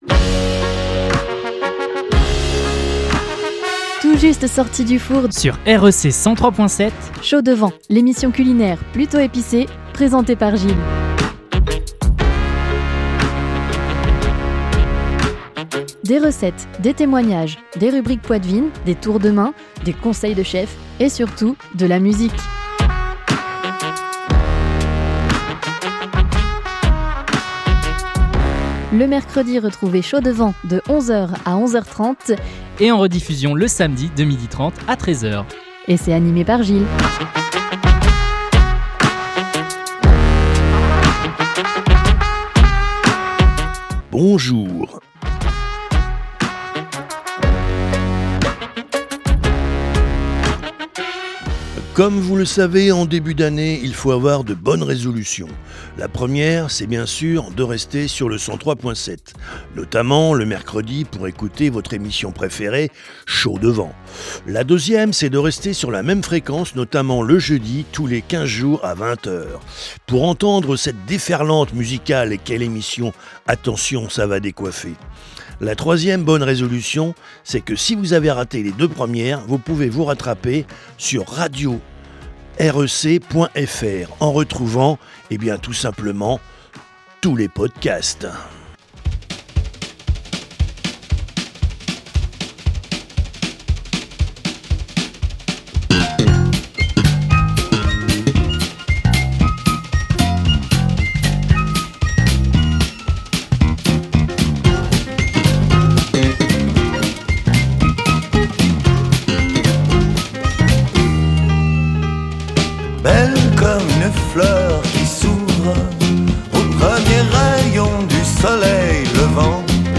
avec anecdotes, témoignages , rubriques , recettes avec des conseils de chef et forcément de la musique !